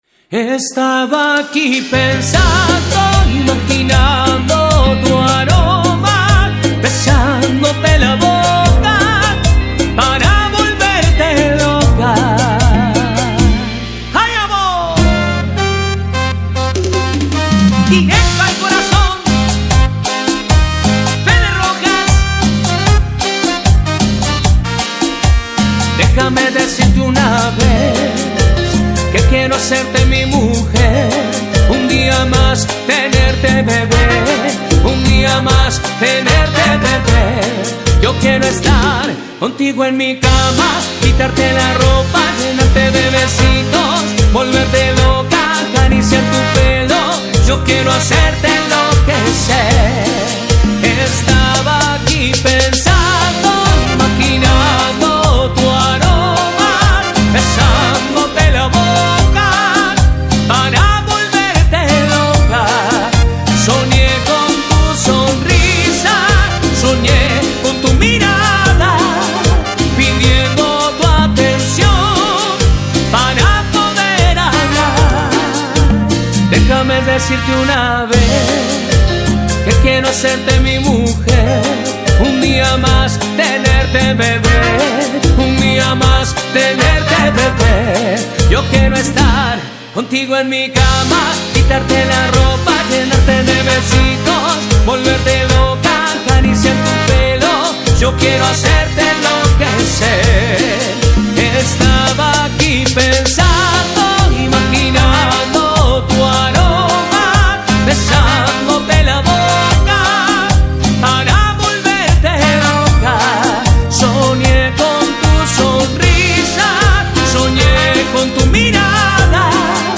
Cumbia y + mp3